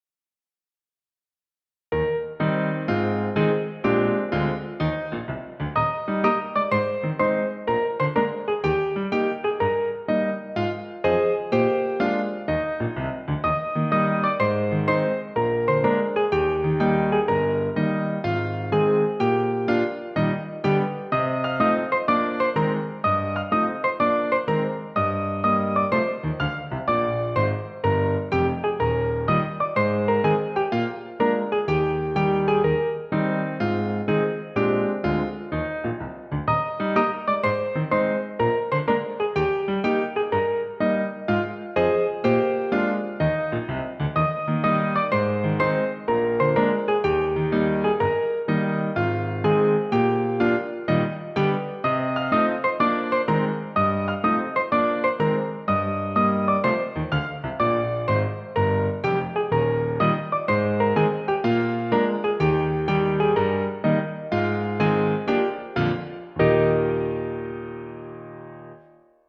Piano accompaniment
Musical Period 19th century British, Australian, American
Tempo 123
Rhythm March
Meter 4/4